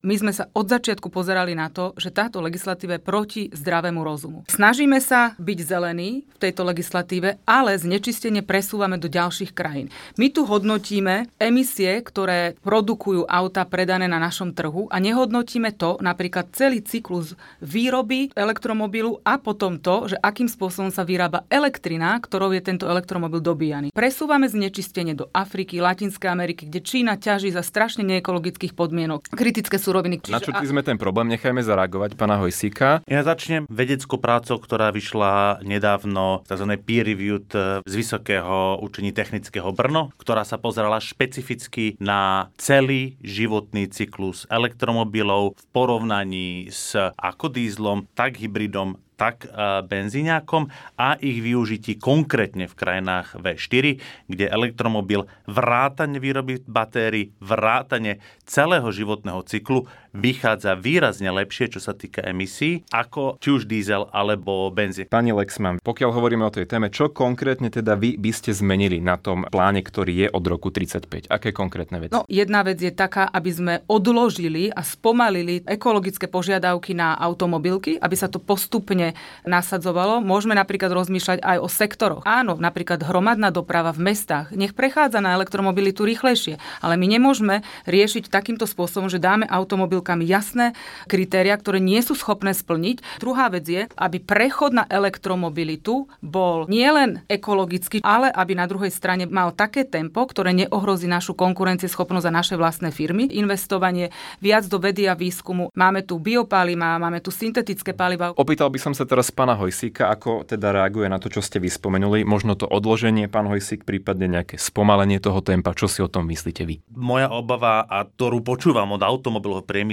Diskusia o aktuálnych témach miest a obcí stredného Slovenska.